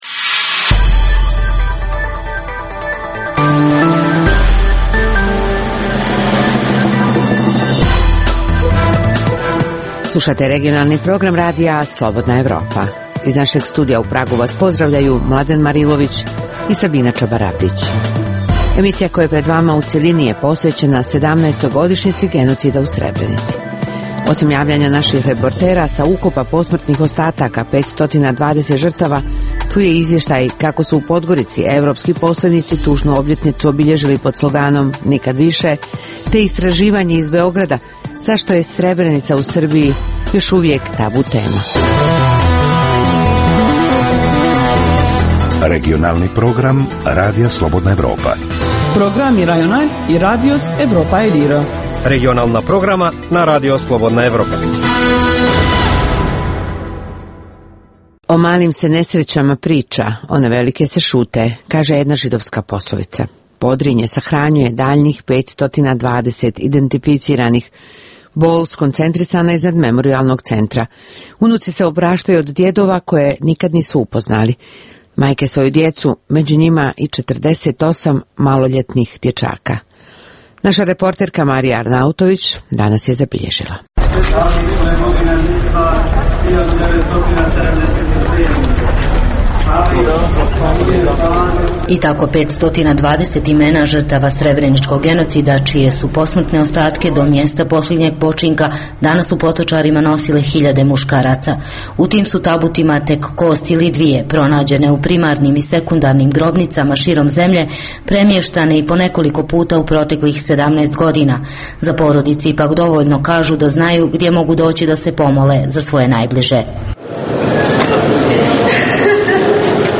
Emisija koja je pred vama u cjelini je posvećena 17-toj godišnjici genocida u Srebrenici. Osim javljanja naših reportera sa ukopa posmrtnih ostataka 520 žrtava, tu je i izvještaj kako su u Podgorici evropski poslenici tužnu obljetnicu obilježili pod sloganom „nikad više“, te istraživanje iz Beograda zašto je Srebrenica u Srbiji još uvijek tabu.